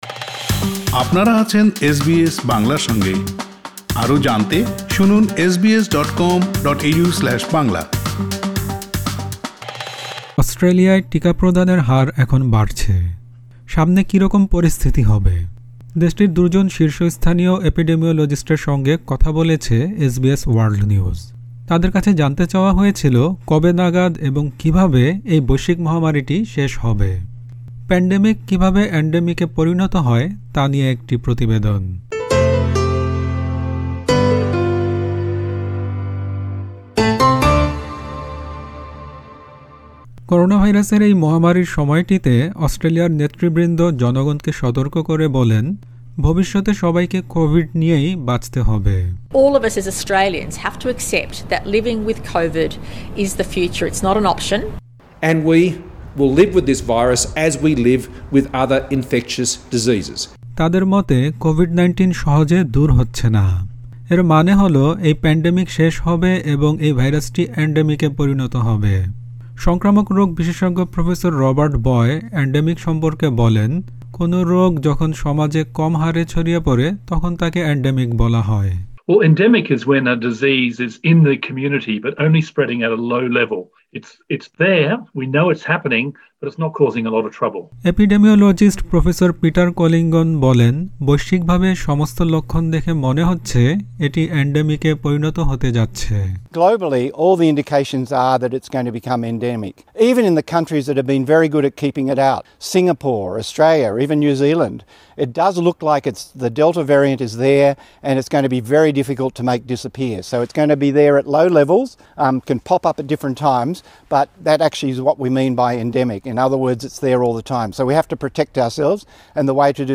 দেশটির দু’জন শীর্ষস্থানীয় এপিডেমিওলজিস্টের সঙ্গে কথা বলেছে এসবিএস ওয়ার্ল্ড নিউজ। তাদের কাছে জানতে চাওয়া হয়েছিল কবে নাগাদ এবং কীভাবে এই বৈশ্বিক মহামারীটি শেষ হবে।